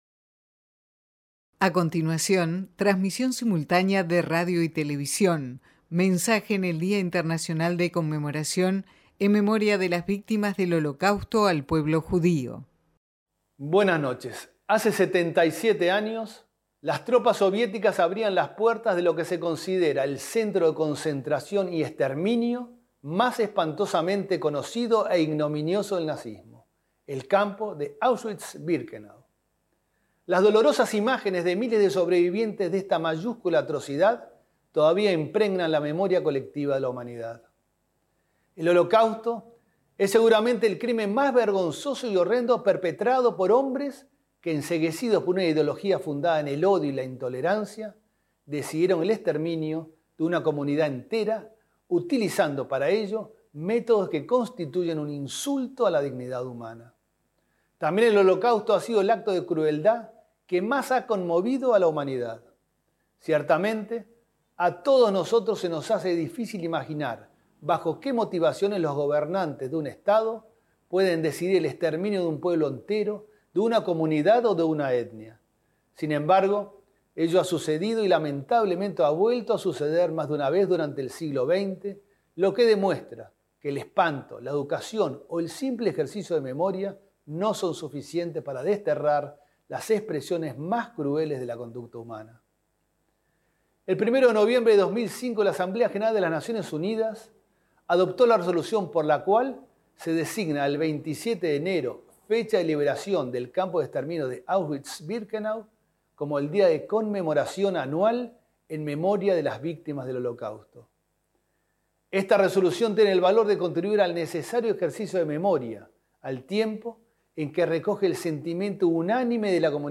Mensaje del Gobierno en el Día Internacional de Conmemoración de las Víctimas del Holocausto
El canciller de la República, Francisco Bustillo, emitió un mensaje, este 27 de enero, con motivo del 77.º aniversario de la liberación de Auschwitz